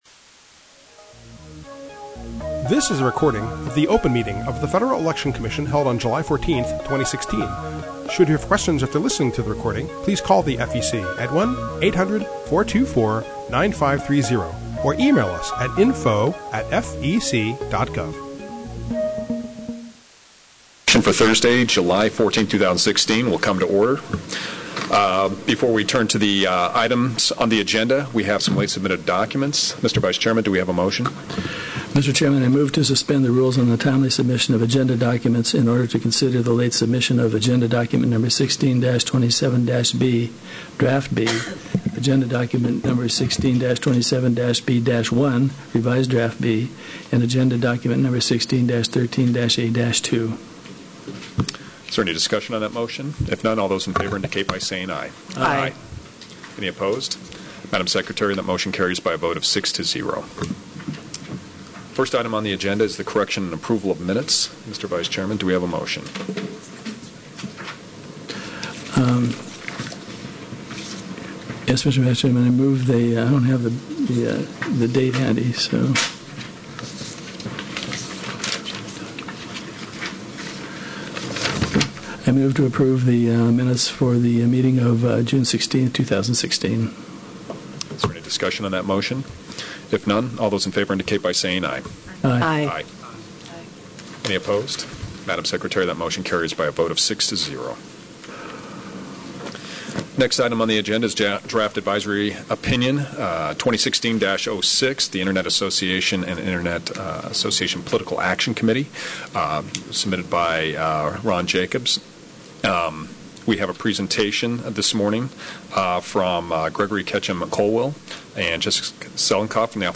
July 14, 2016 open meeting | FEC
Open Meeting Agenda